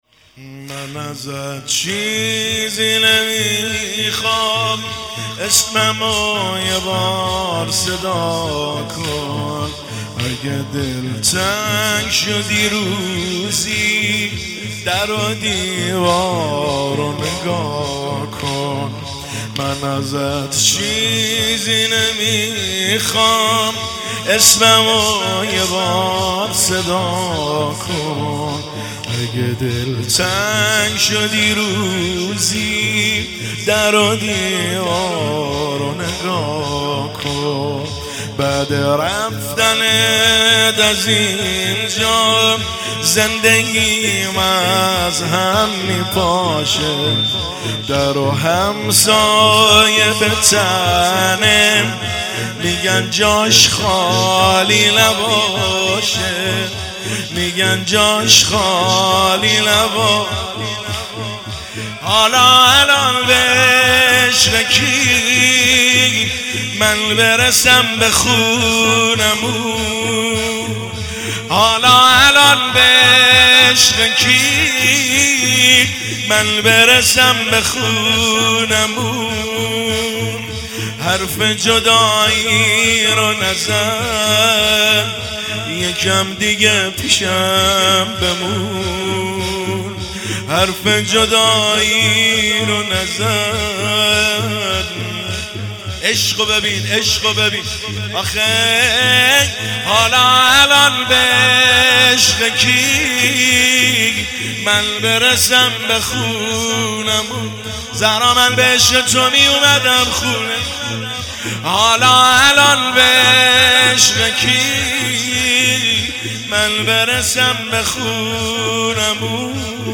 دانلود مداحی زمینه
فاطمیه دوم 1403
دانلود نوحه زمینه شهادت حضرت زهرا